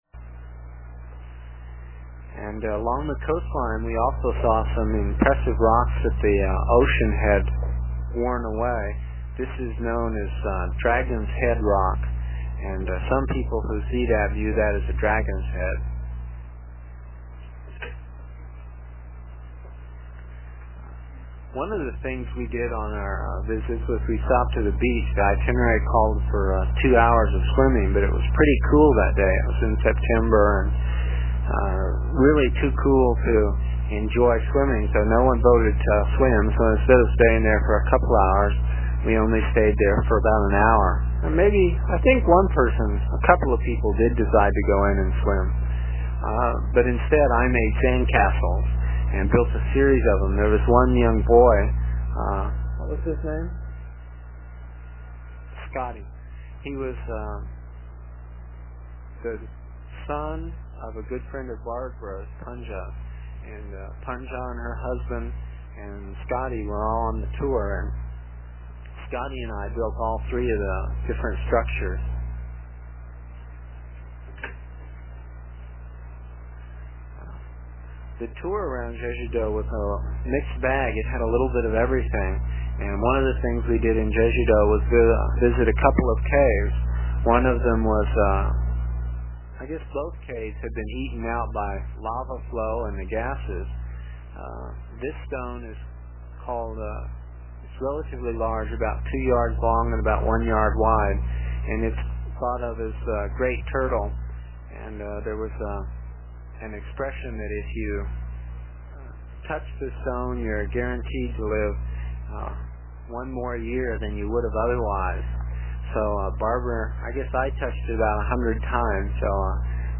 It is from the cassette tapes we made almost thirty years ago. I was pretty long winded (no rehearsals or editting and tapes were cheap) and the section for this page is about six minutes and will take about two minutes to download with a dial up connection.